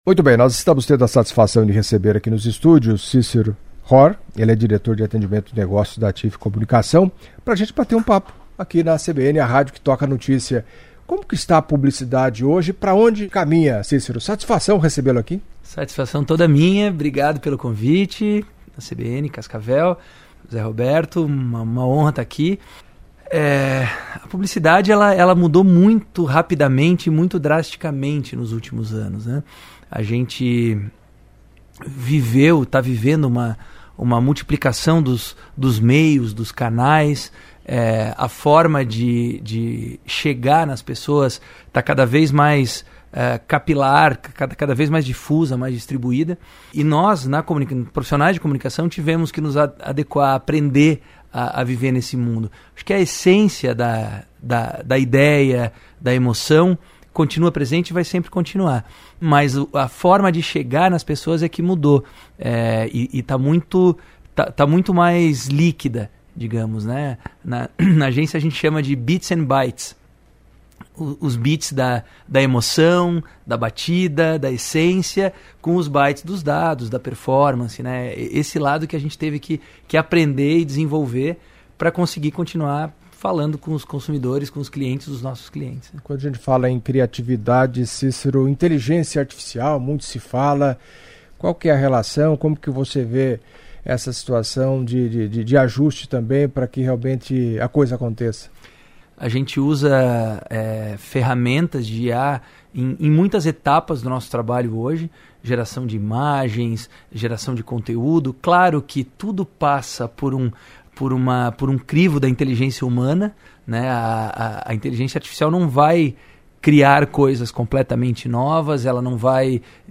Em entrevista à CBN Cascavel nesta segunda-feira (11)